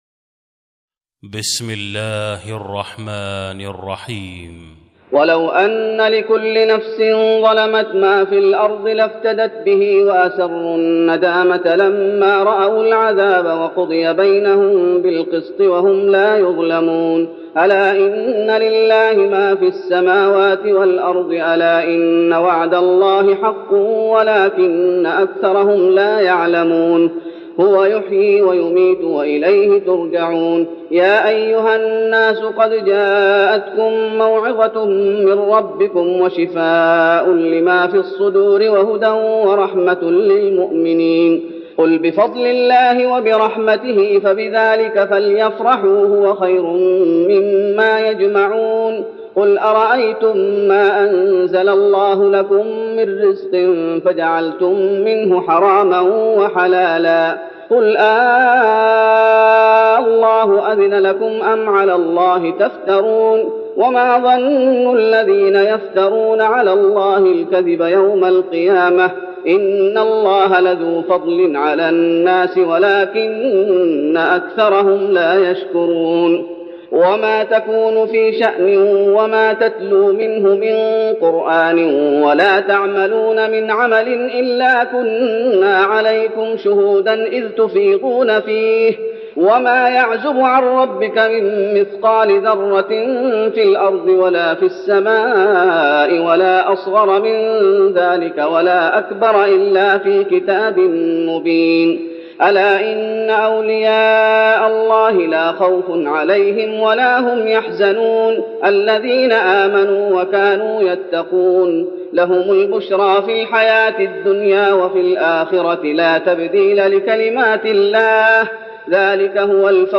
تراويح رمضان 1412هـ من سورة يونس (54-109) Taraweeh Ramadan 1412H from Surah Yunus > تراويح الشيخ محمد أيوب بالنبوي 1412 🕌 > التراويح - تلاوات الحرمين